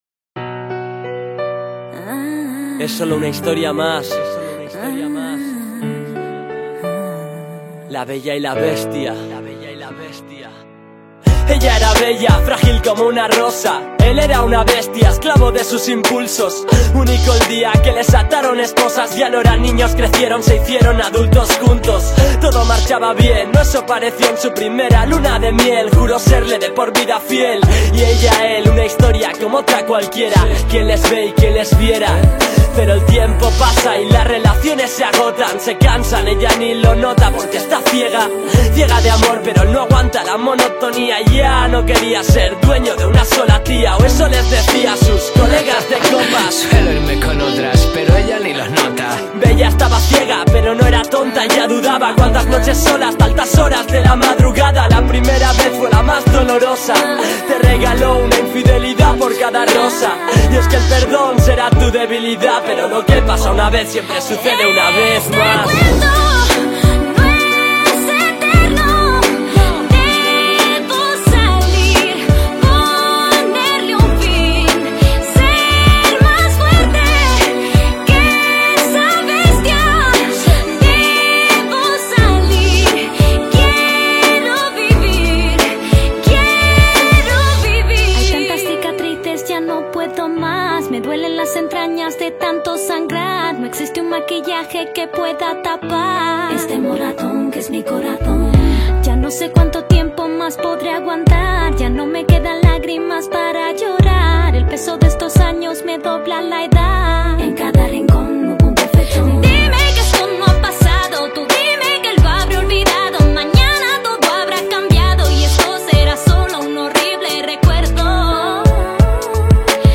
Hay un rap